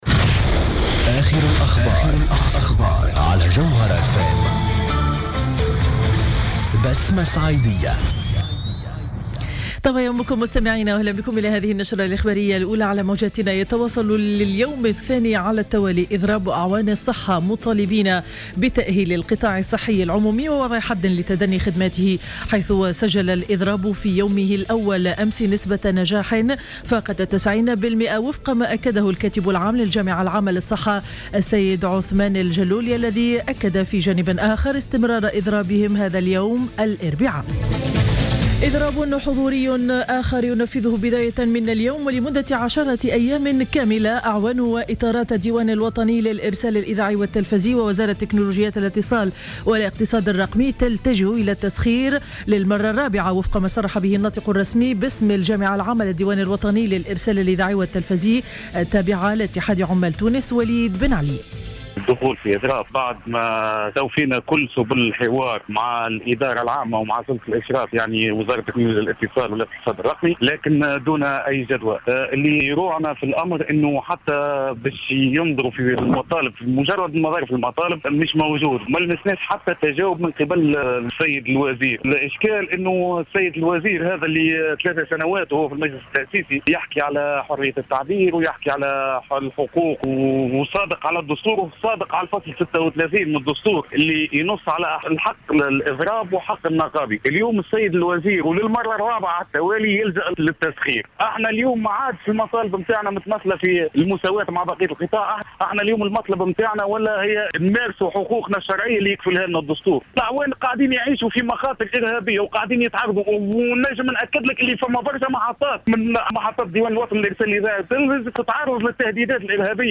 نشرة أخبار السابعة صباحا ليوم الإربعاء 29 أفريل 2015